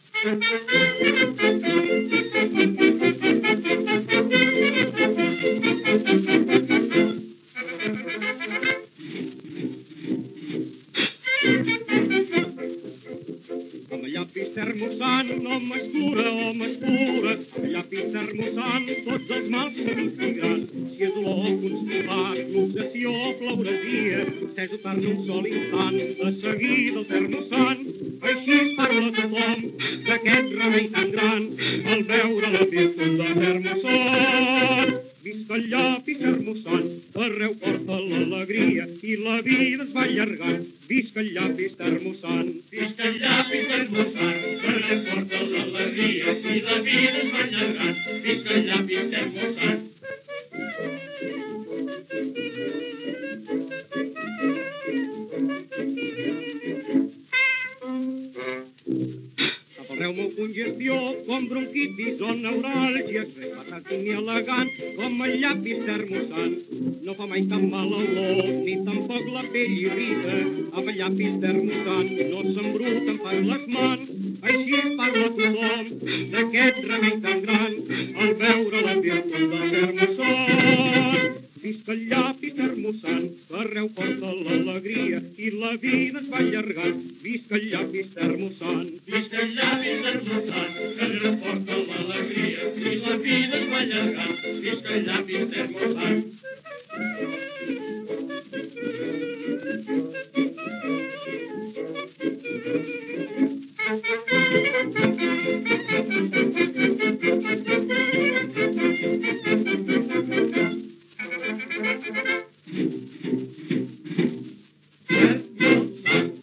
Publicitat cantada